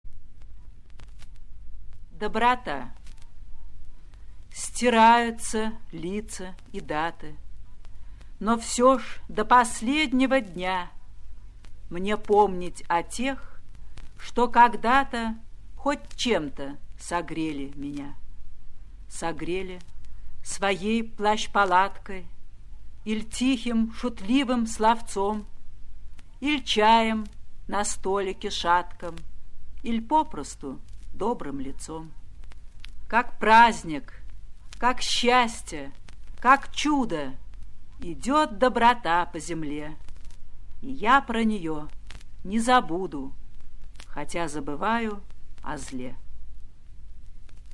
3. «Юлия Друнина – Доброта (читает автор)» /
Drunina-Dobrota-chitaet-avtor-stih-club-ru.mp3